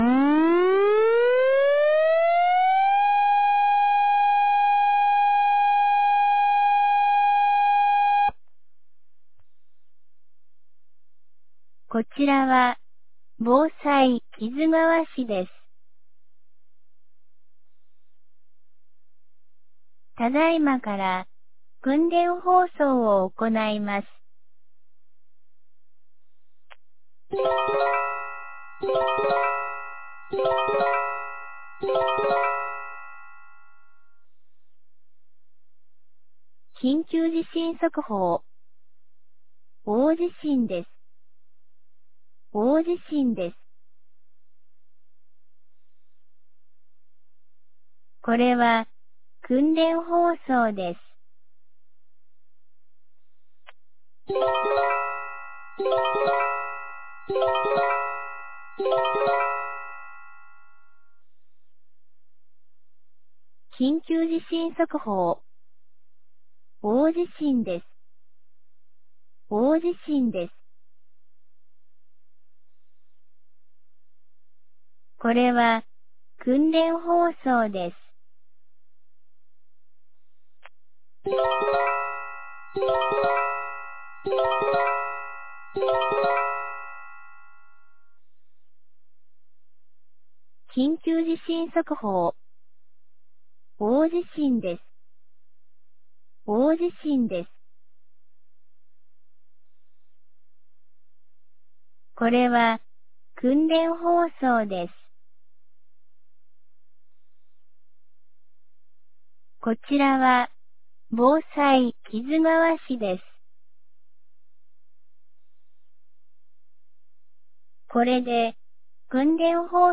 2025年06月18日 10時02分に、木津川市より市全域へ放送がありました。
放送音声